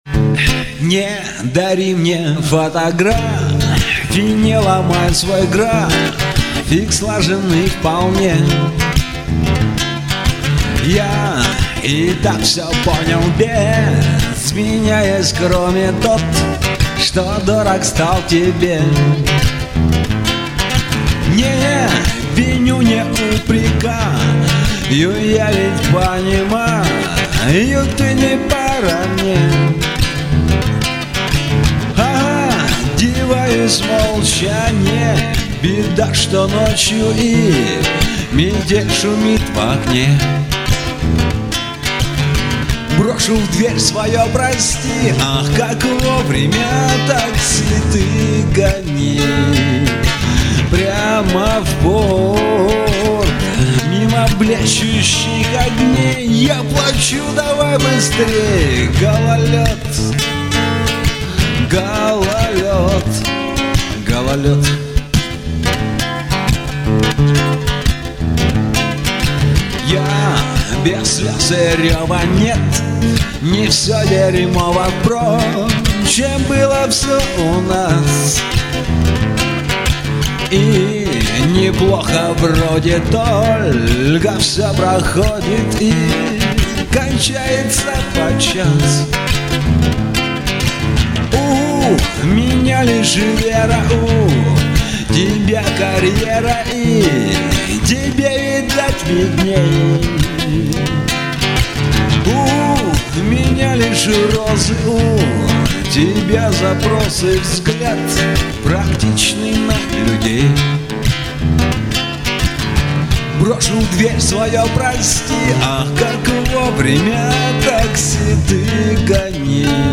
Несколько песен одного из участников концерта "О чем поют мужчины".